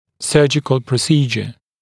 [‘sɜːʤɪkl prə’siːʤə][‘сё:джикл прэ’си:джэ]хирургическая процедура, хирургическая манипуляция